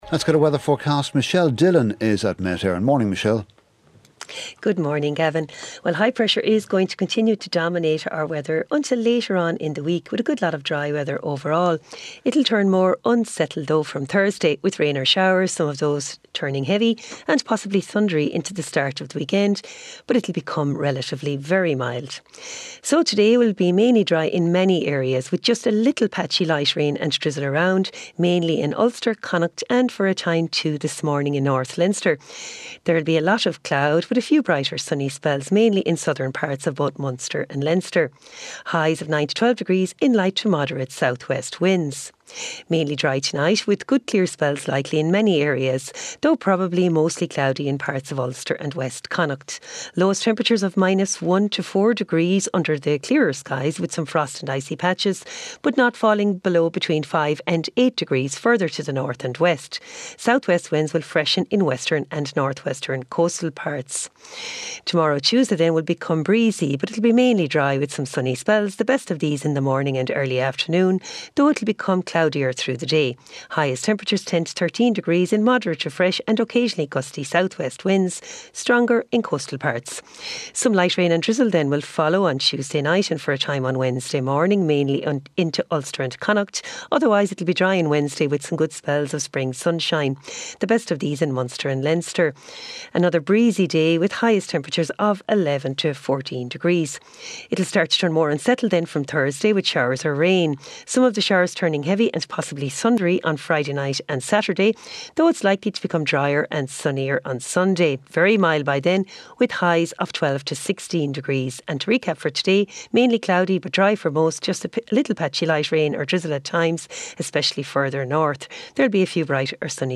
7:35am Sports News - 03.03.2025